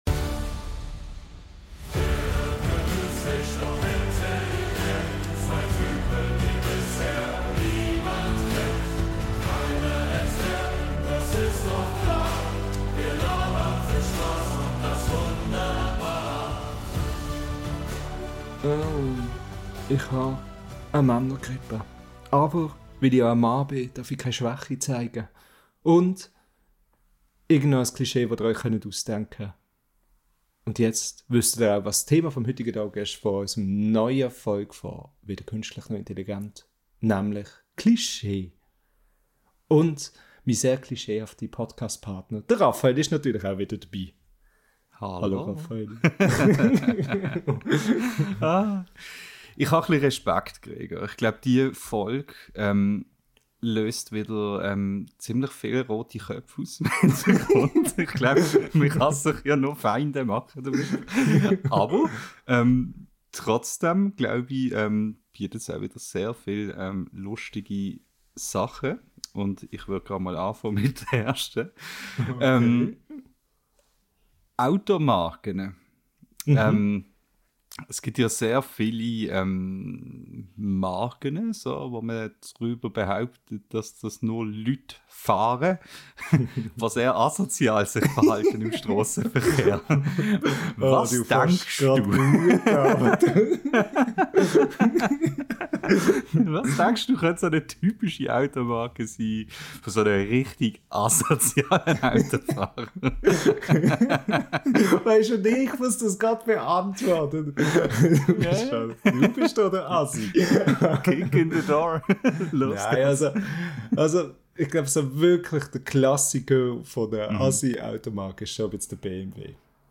In dieser Folge unseres schweizerdeutschen Podcasts wimmelt es nur so von Klischees: Warum dürfen Männer keine Schwäche zeigen?